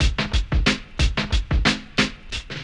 Summer Drum Break1.wav